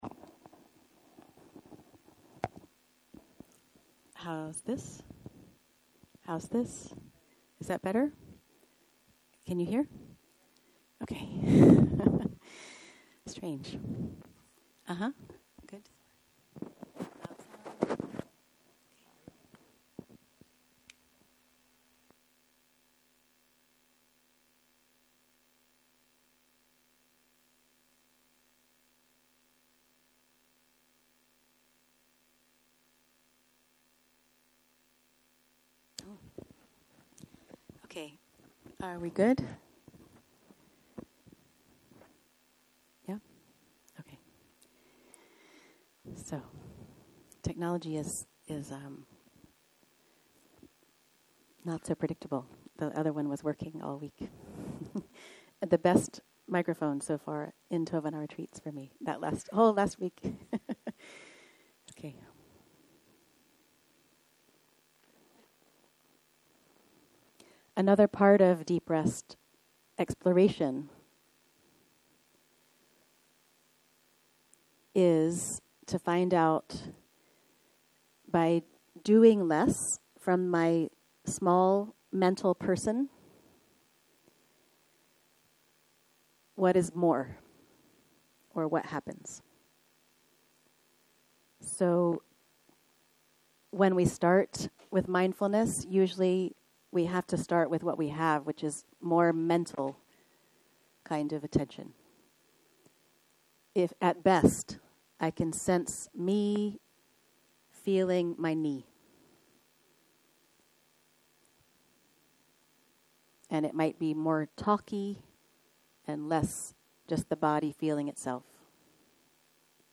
Dharma type: Opening talk שפת ההקלטה